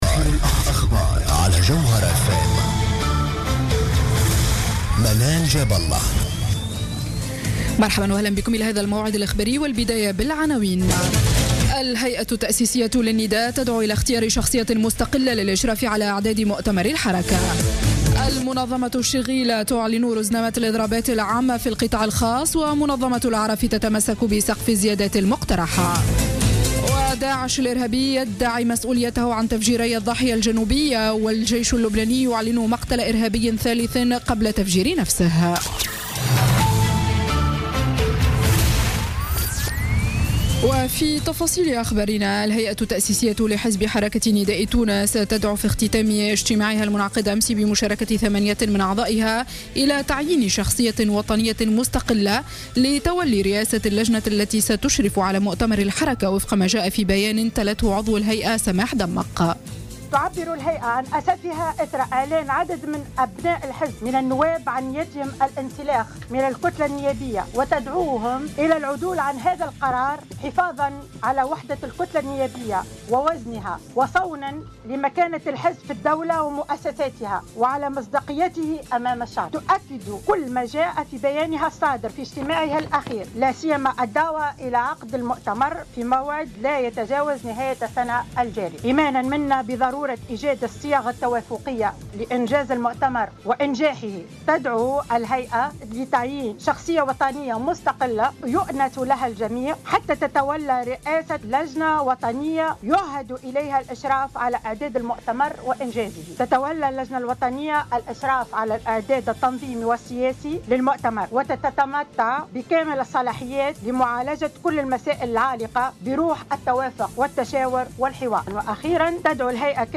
نشرة أخبار منتصف الليل ليوم الجمعة 13 نوفمبر 2015